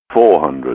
IDG-A32X/Sounds/GPWS/altitude-400.wav at 34be6e9e1adea81e86029b92c186d6cca43ef494